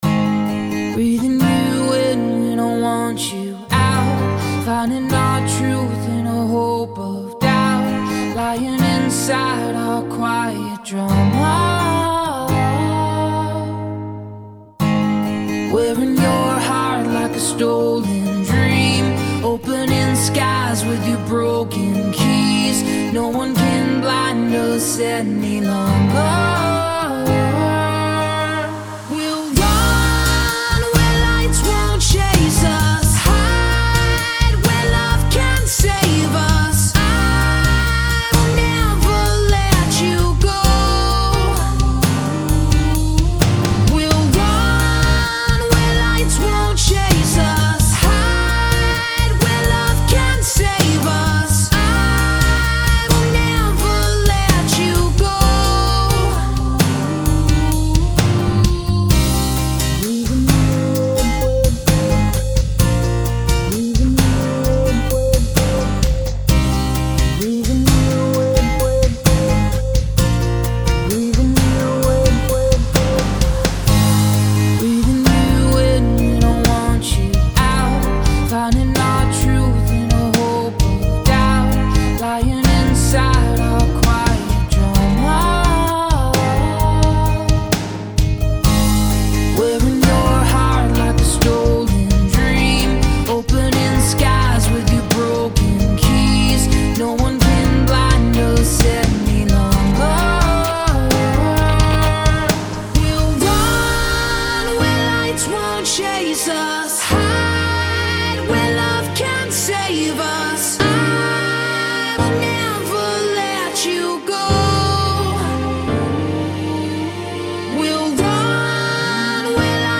Country Version